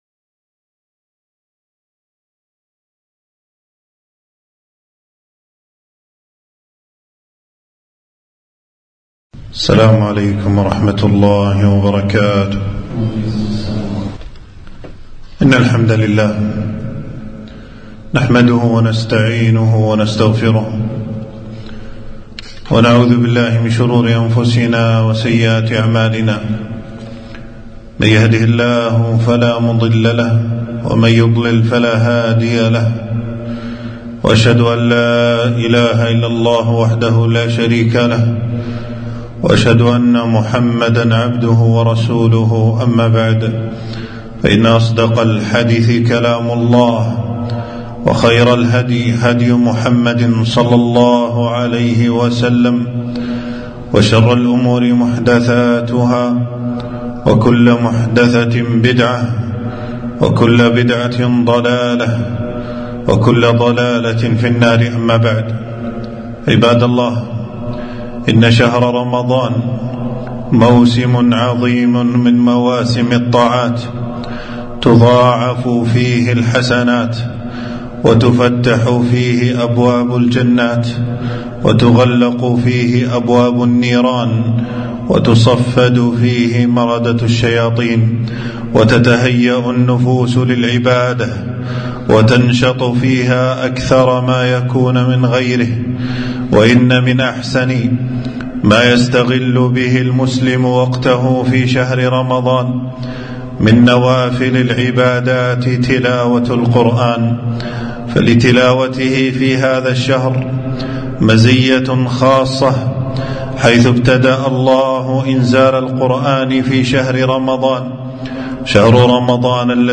خطبة - الترغيب في تلاوة القرآن وقيام ليل رمضان